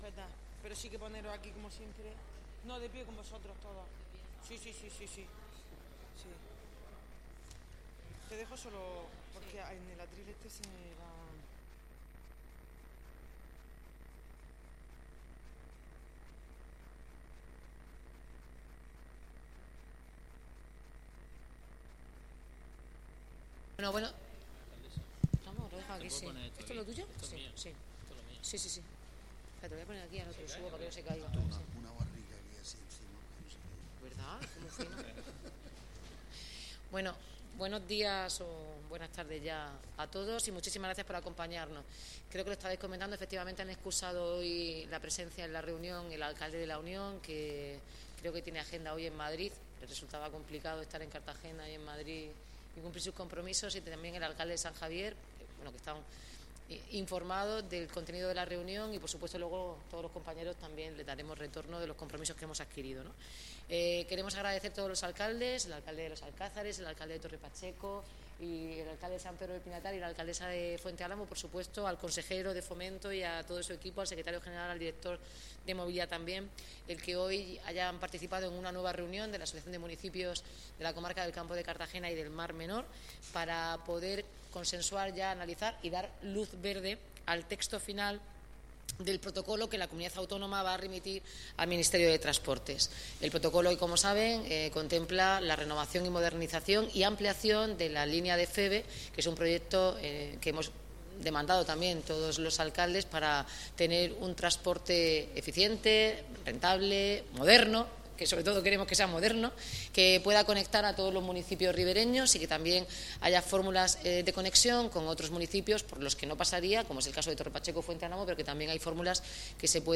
Enlace a Declaraciones de la alacaldesa y el consejero de Fomento tras reunión Asociación de Alcaldes de la Comarca de Cartagena y Mar Menor